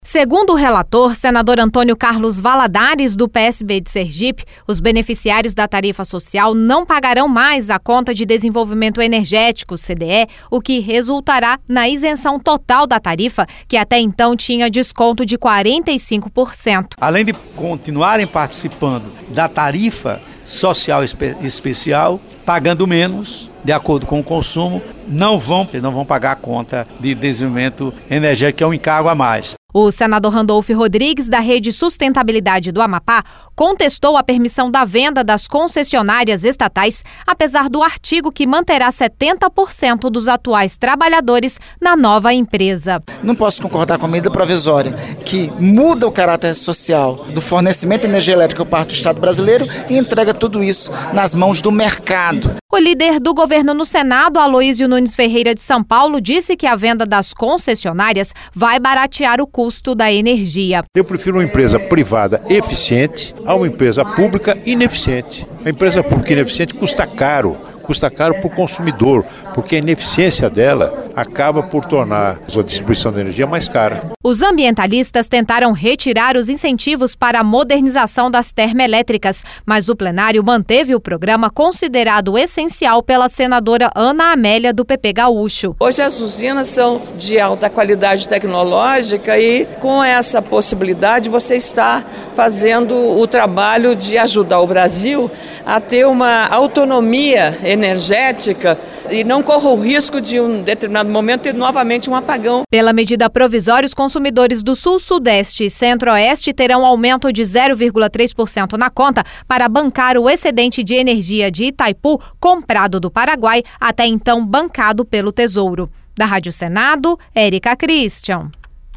A repórter